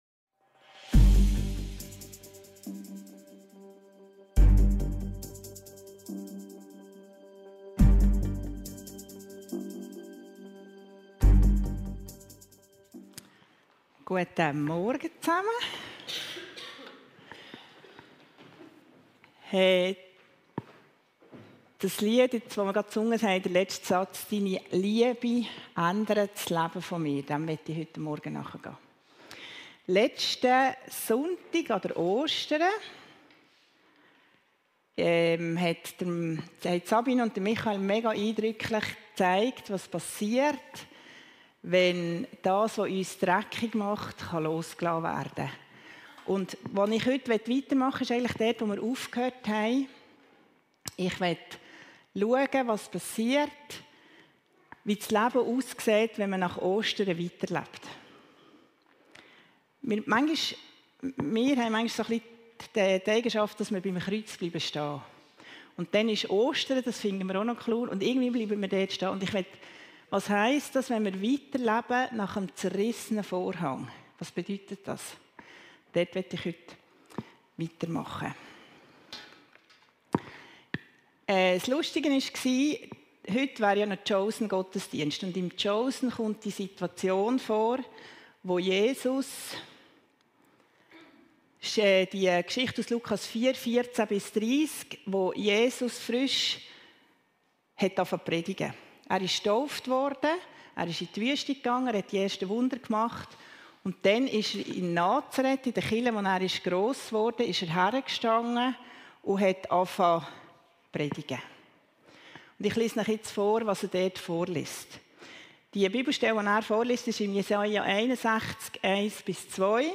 Audio-Predigten der Kirche Wigarten / Fällanden (CH)